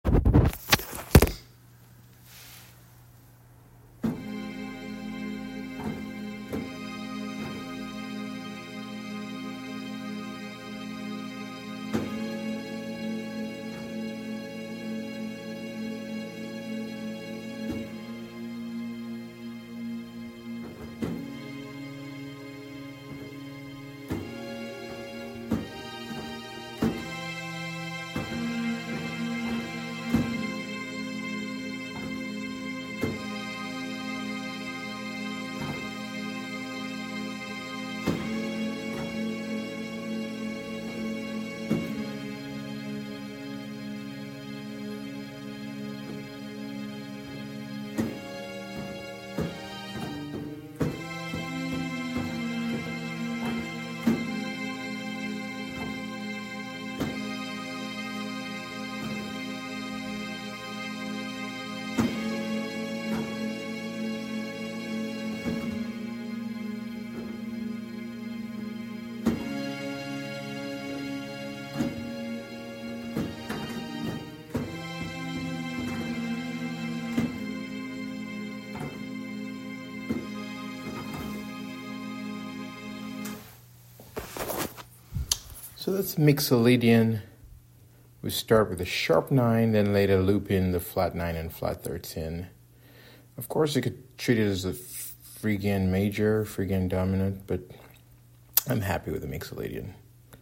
To further elucidate this, I’ve added an audio of strings to emphasize how the Mixolydian and Phrygian modes often interact in dialogue (a process I term MAP'D).